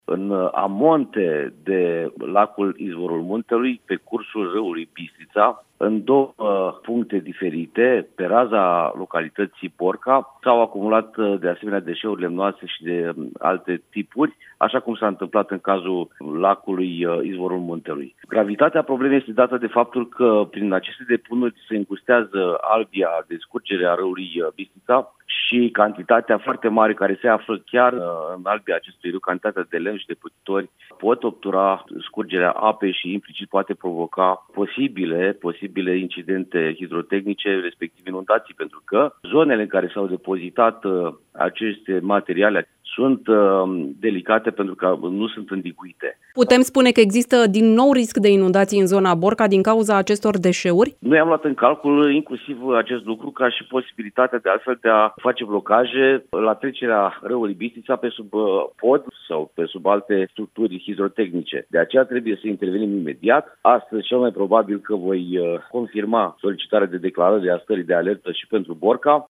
Prefectul Adrian Bourceanu: